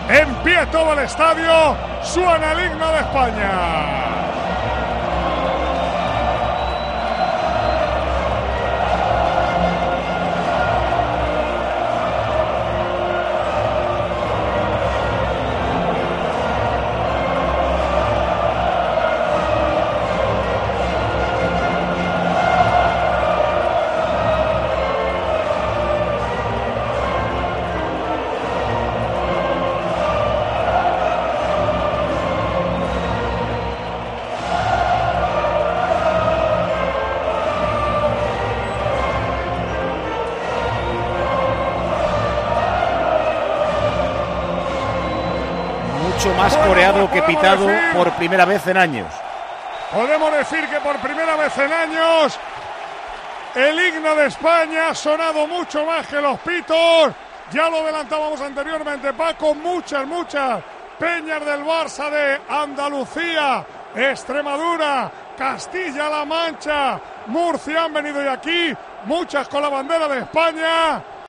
El himno de España, mucho más coreado que pitado en la Final de la Copa del Rey
Por primera vez en años, el himno de España fue más coreado que pitado.